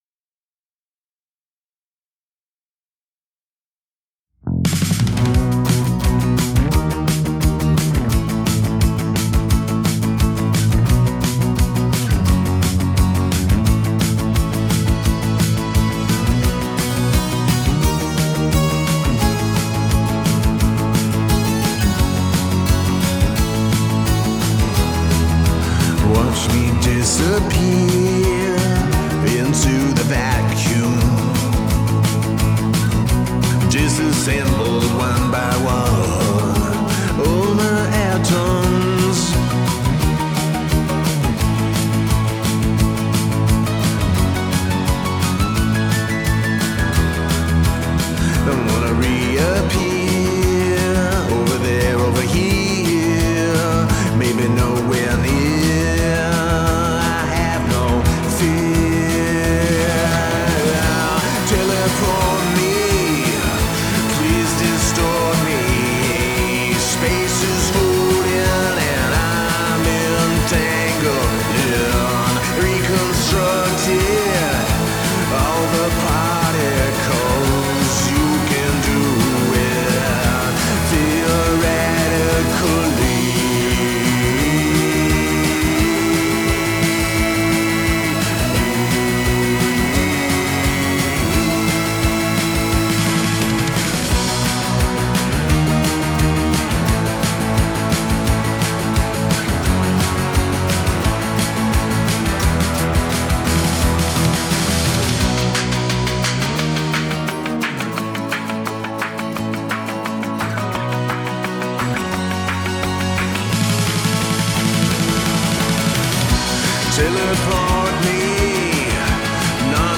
Such dystopian new wave feels!